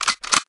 bandit_reload_01.ogg